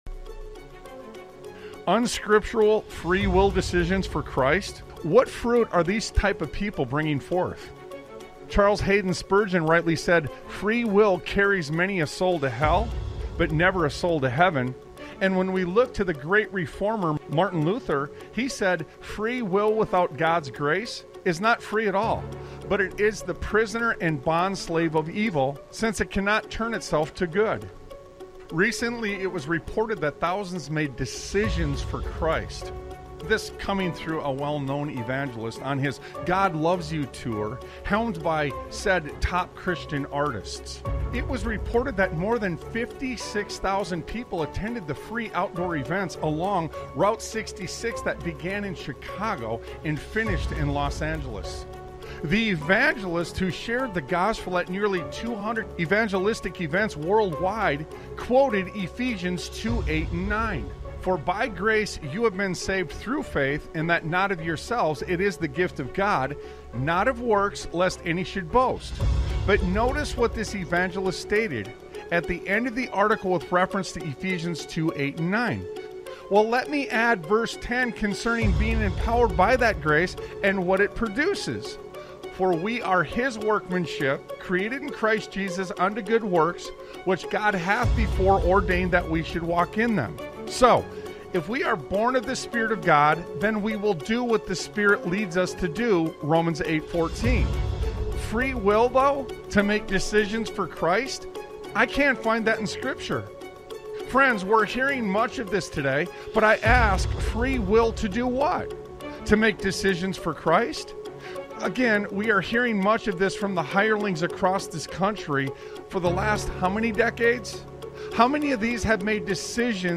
Talk Show Episode, Audio Podcast, Sons of Liberty Radio and No Such Thing on , show guests , about No Such Thing, categorized as Education,History,Military,News,Politics & Government,Religion,Christianity,Society and Culture,Theory & Conspiracy